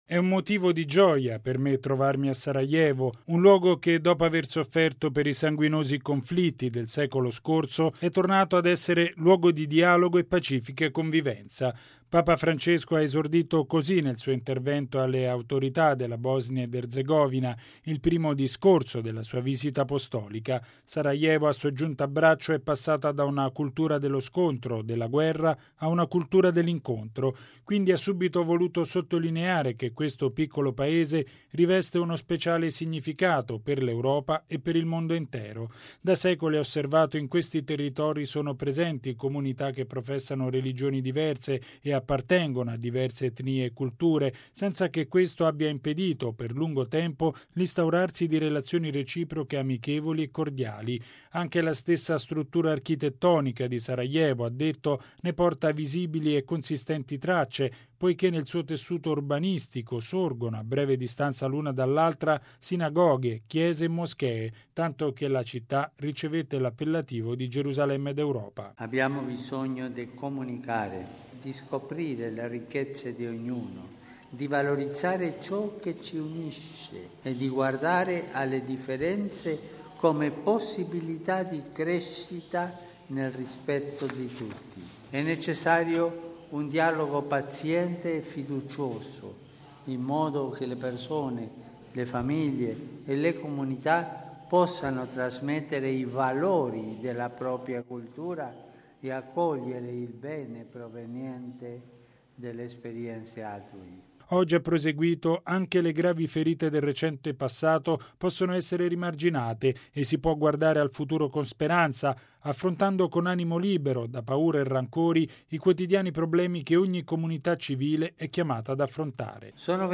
E’ quanto affermato da Papa Francesco nel suo primo discorso in Bosnia ed Erzegovina, tenuto alle autorità del Paese nel Palazzo presidenziale.